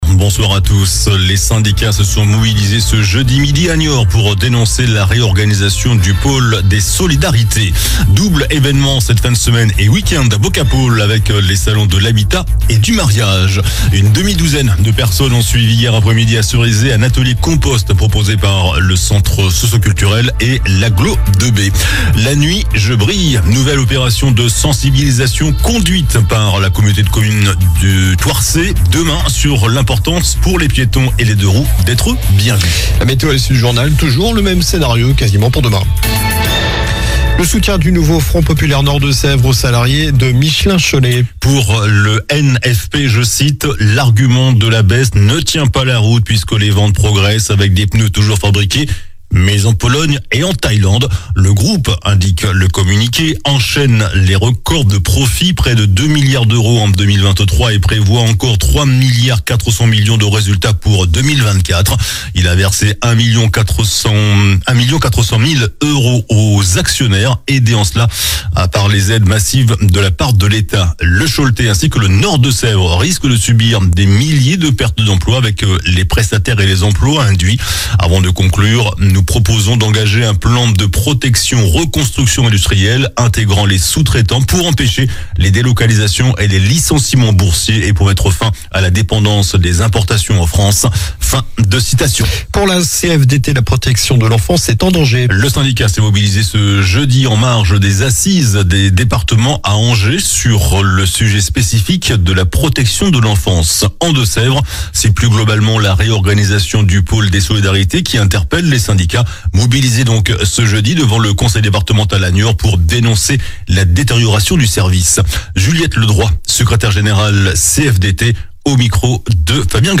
JOURNAL DU JEUDI 14 NOVEMBRE ( SOIR )